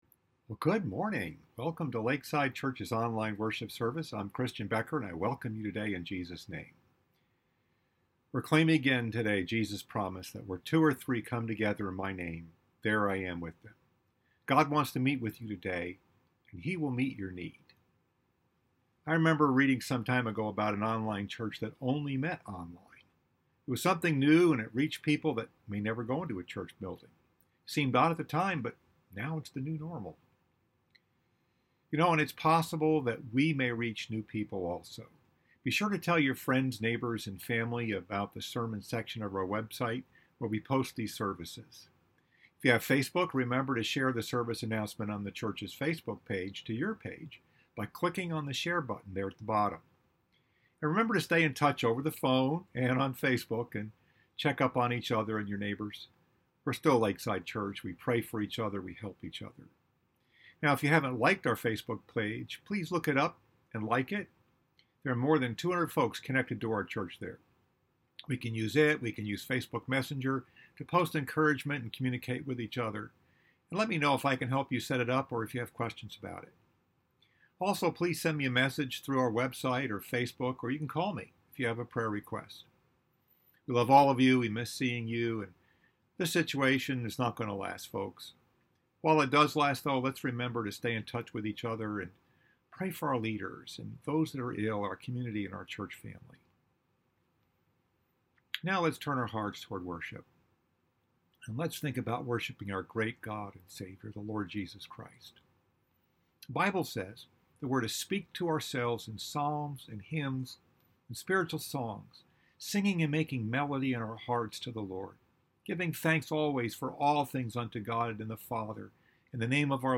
Message: “What’s the Next Step?” Part 2 Scripture: John 21